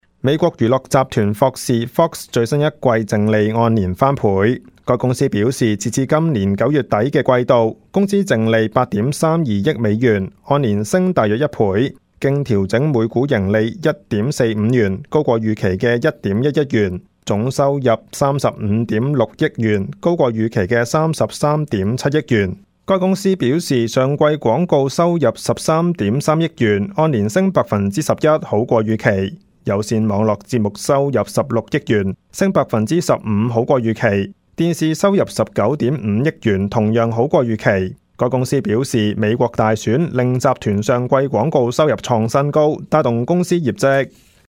Canada/World News 全國/世界新聞
news_clip_21146.mp3